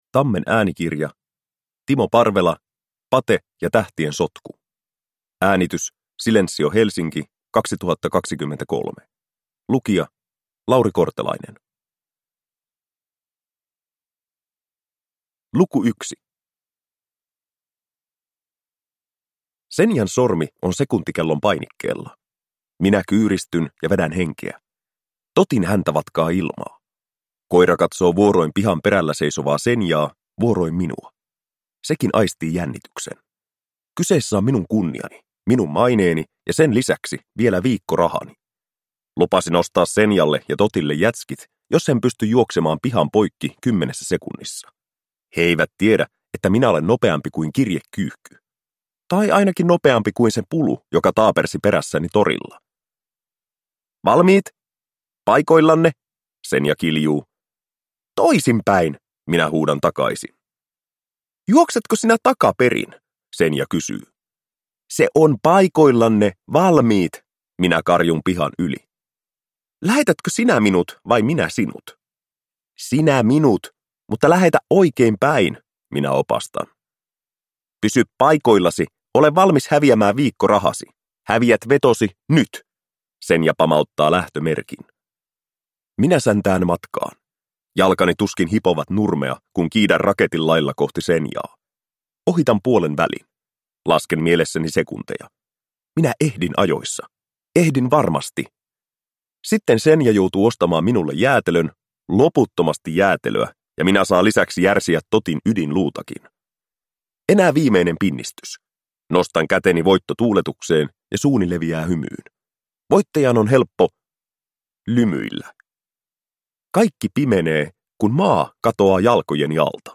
Pate ja tähtien sotku – Ljudbok